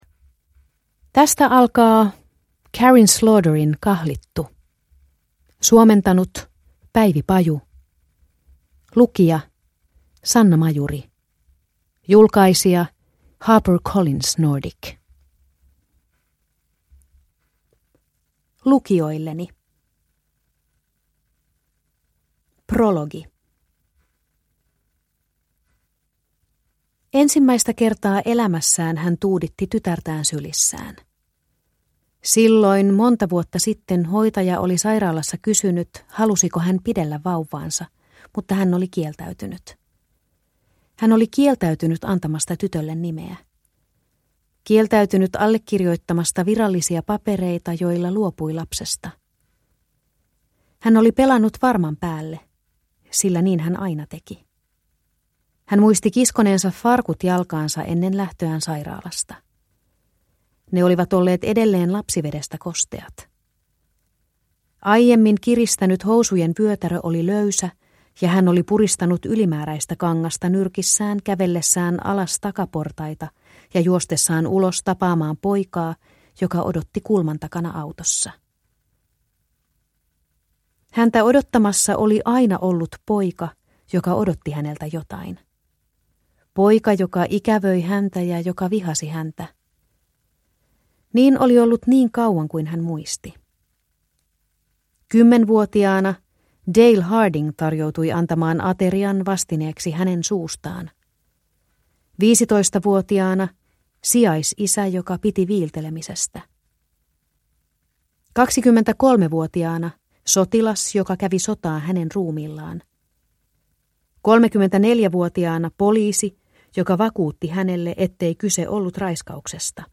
Kahlittu – Ljudbok – Laddas ner